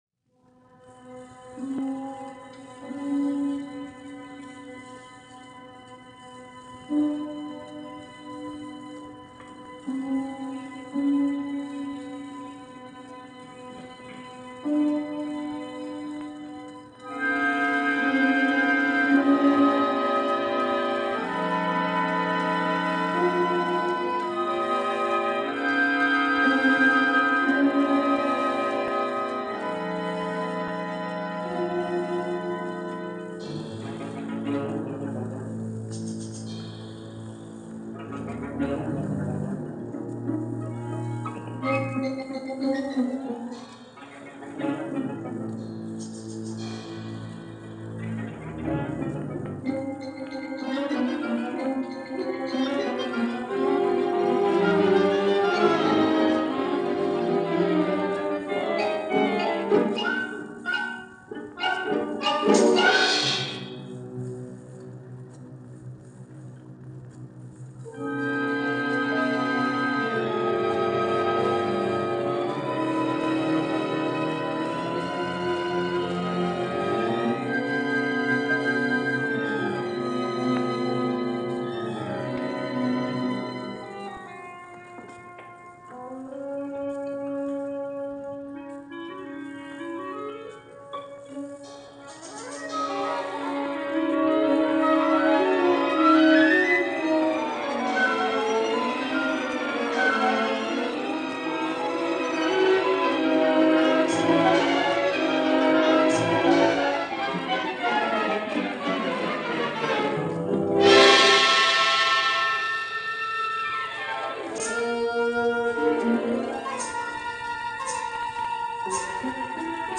André Cluytens leads the French National Orchestra in a broadcast performance of music by Debussy in 1953 - Past Daily Weekend Gramophone
The legendary Belgian conductor André Cluytens this weekend. A performance of Debussy’s orchestral work, Jeux, as it was given with the French National Orchestra in this radio broadcast via the ORTF in Paris, circa 1953.